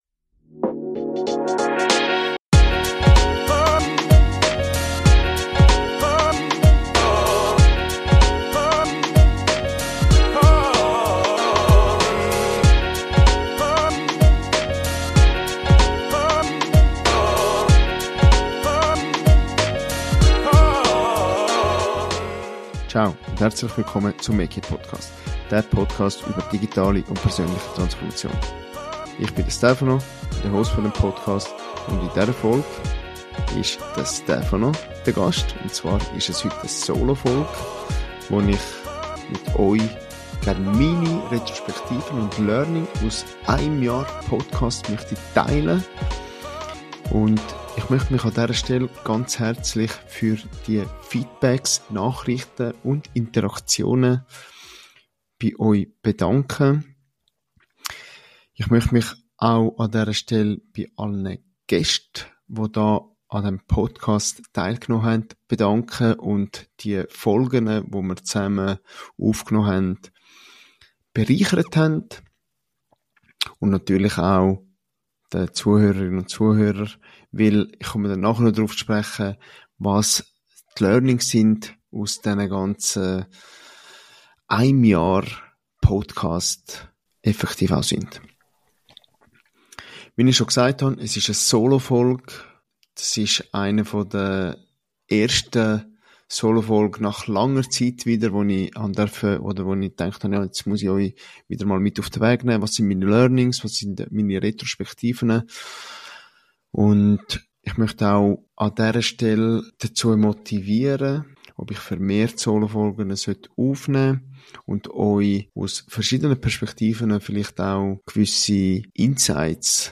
Beschreibung vor 1 Jahr In dieser Solo-Folge gehe ich auf mein erstes Jahr Podcast ein und teile meine Learnings und Herausforderungen.